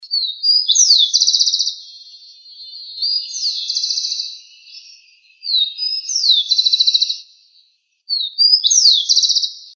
Rufous-collared Sparrow (Zonotrichia capensis)
Life Stage: Adult
Location or protected area: Reserva Ecológica Costanera Sur (RECS)
Condition: Wild
Certainty: Recorded vocal
RECS.Chingolo-Sony-estereo.mp3